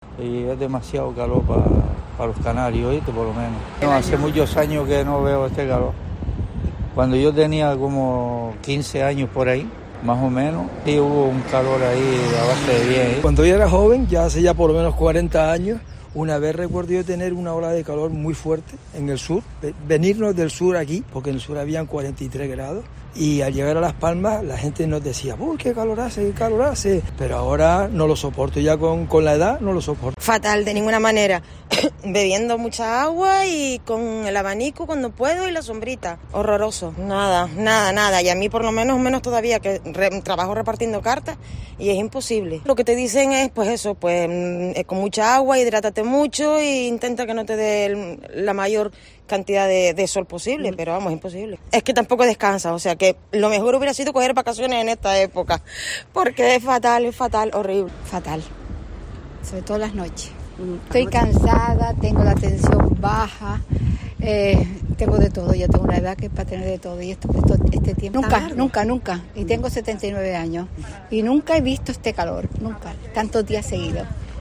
Vecinos de la capital grancanaria cuentan cómo combaten el aire caliente, las altas temperaturas y la calima